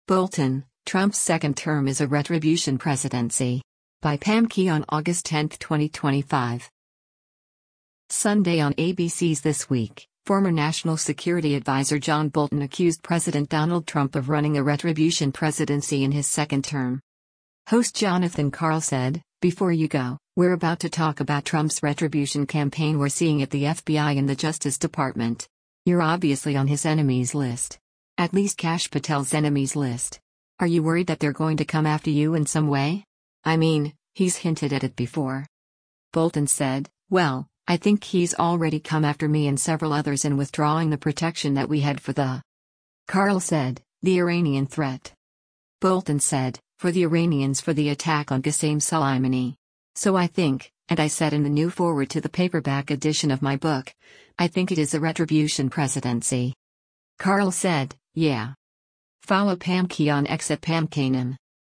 Sunday on ABC’s “This Week,” former National Security Advisor John Bolton accused President Donald Trump of running a “retribution presidency” in his second term.